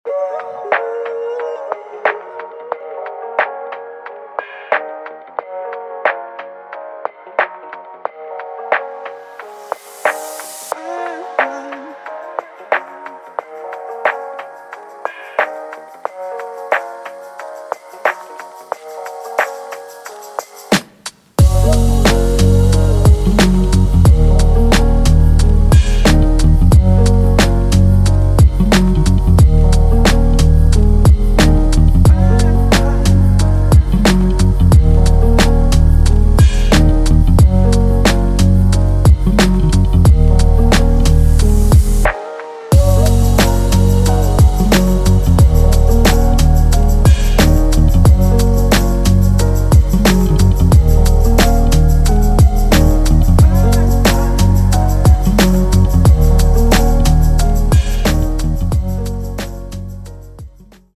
Trip Hop